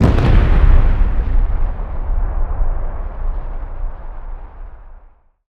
bigboom_converted.wav